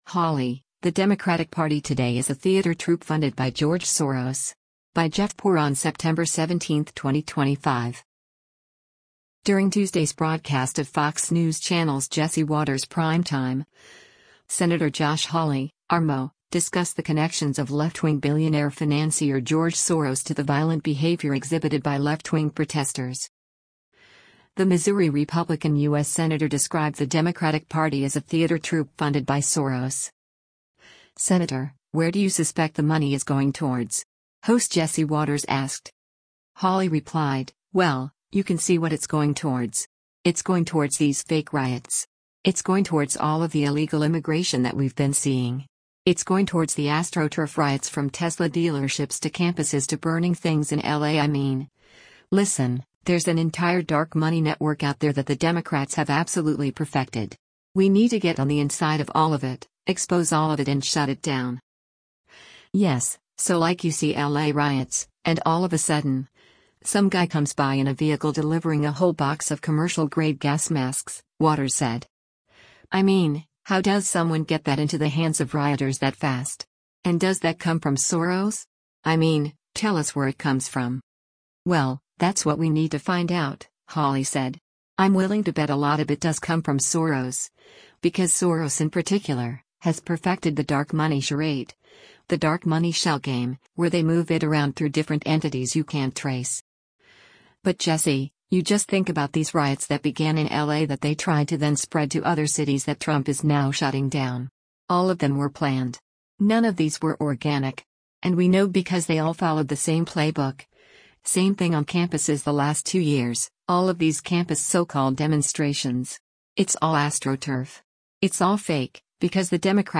During Tuesday’s broadcast of Fox News Channel’s “Jesse Watters Primetime,” Sen. Josh Hawley (R-MO) discussed the connections of left-wing billionaire financier George Soros to the violent behavior exhibited by left-wing protesters.
“Senator, where do you suspect the money is going towards?” host Jesse Watters asked.